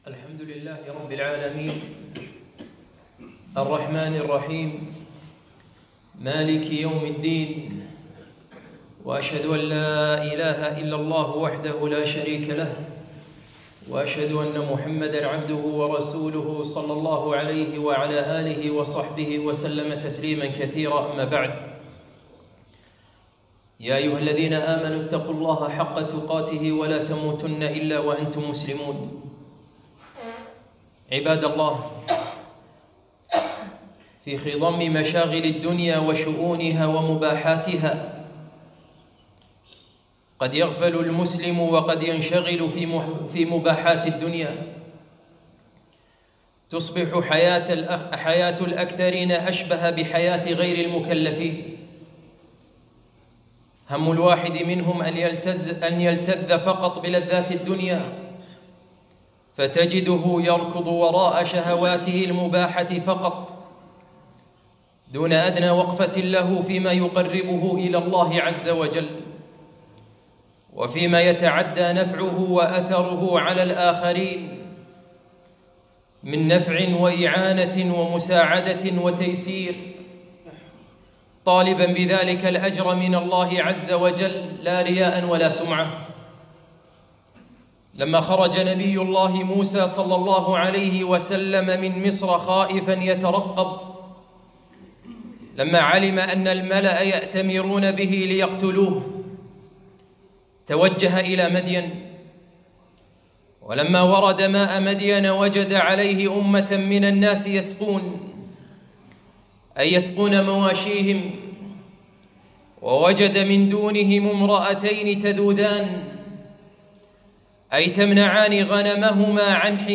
خطبة - الأعمال التطوعية